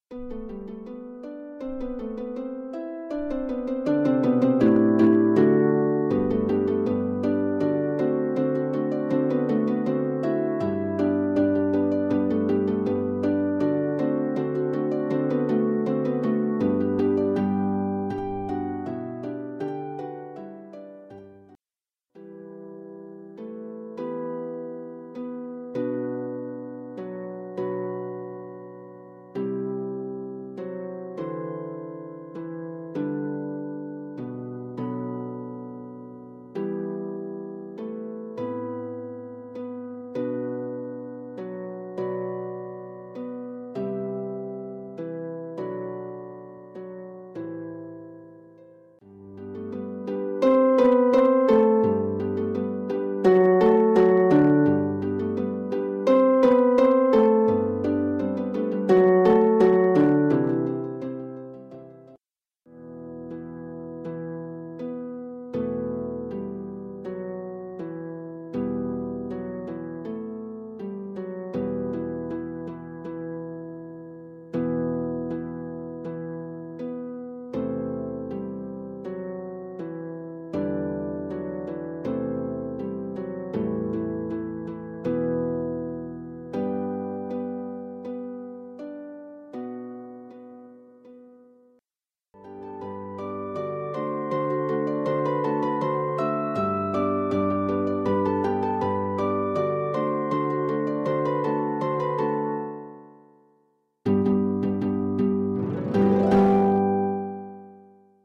Arranged for four lever or pedal harps
four traditional Welsh melodies